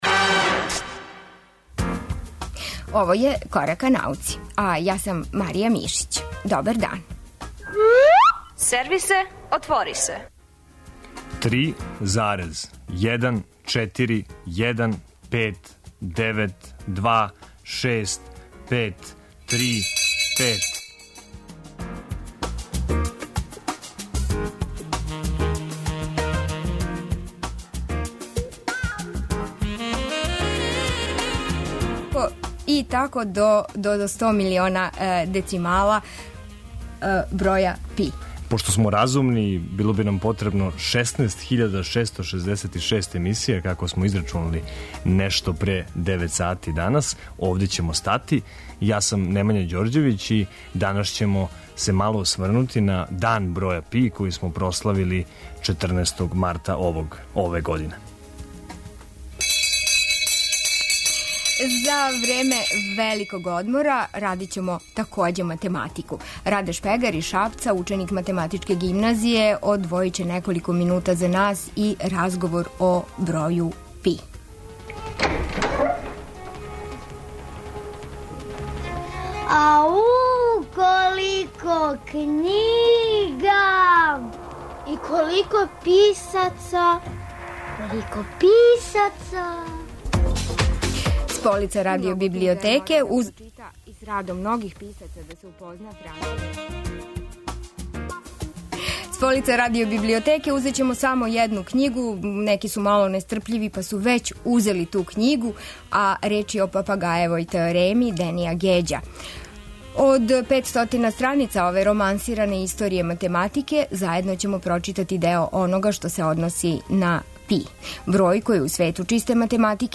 Разговарамо такође и са младим физичарима који су у понедељак, на рођендан Алберта Ајнштајна, били учесници радионице из области физике честица.